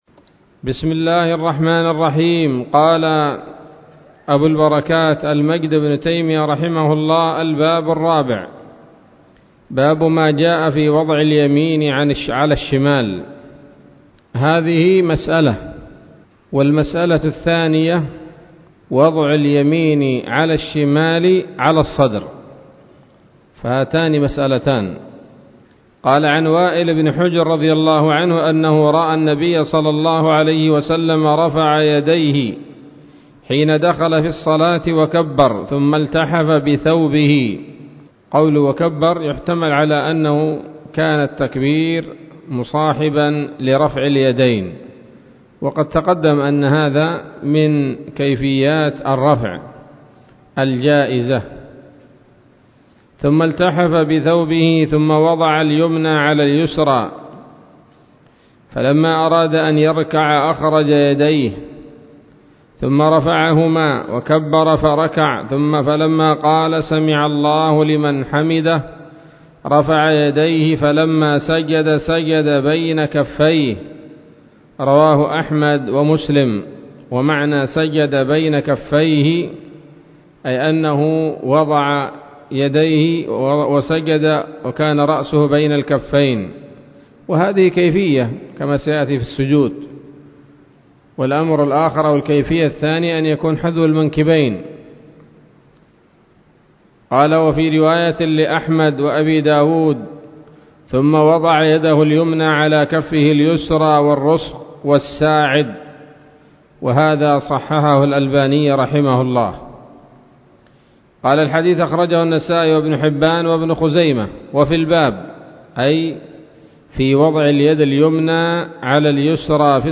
الدرس الحادي عشر من أبواب صفة الصلاة من نيل الأوطار